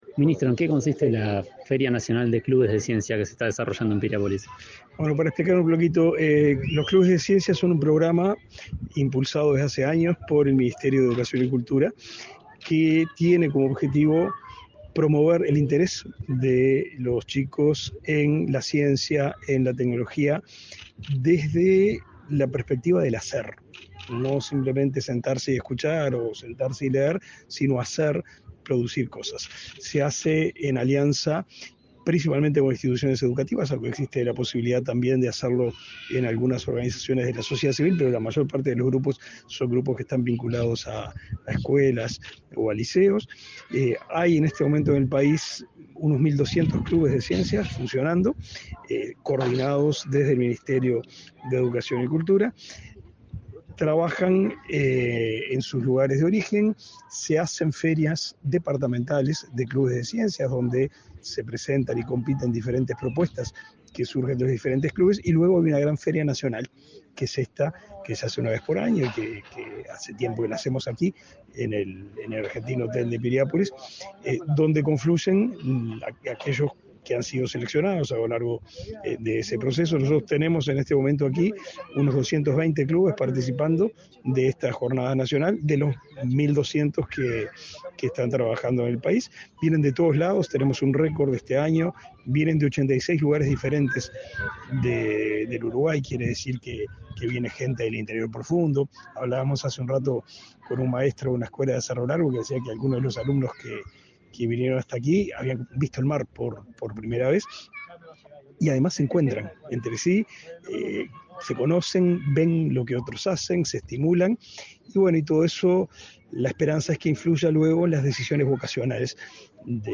Entrevista al ministro de Educación y Cultura, Pablo da Silveira
El ministro de Educación y Cultura, Pablo da Silveira, participó, este 16 de noviembre, en la apertura de la Feria Nacional de Clubes de Ciencia 2023.
En la oportunidad, el jerarca realizó declaraciones a Comunicación Presidencial.